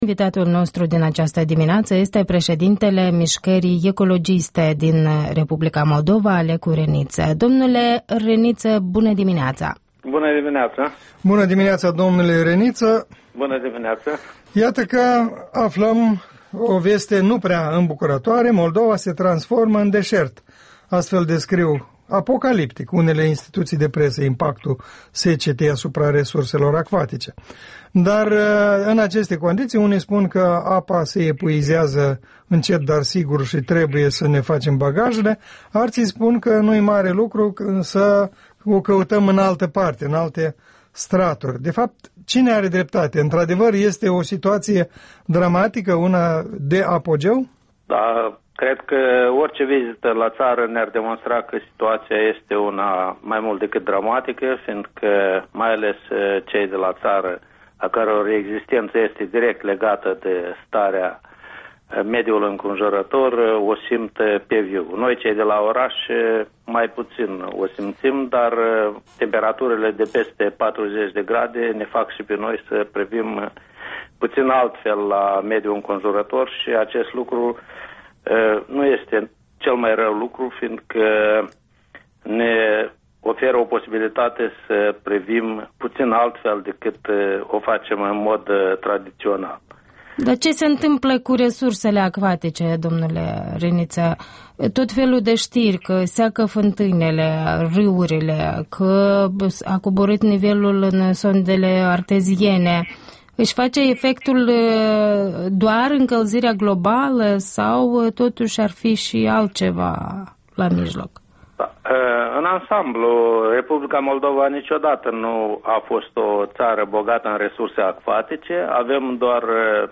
Interviul dimineții la REL: cu Alecu Reniță despre efectele secetei și arșiței din Moldova